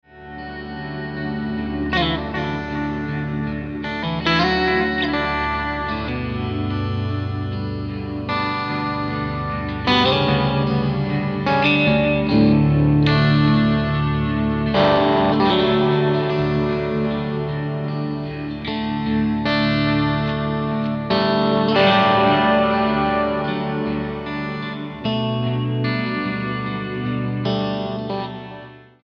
STYLE: Rock
to go from tender to fierce in one breath